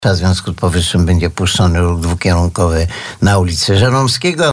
– Związku z powyższym na ulicy Żeromskiego będzie puszczony ruch dwukierunkowy – zapowiadał dzisiaj na naszej antenie burmistrz Antoni Szlagor.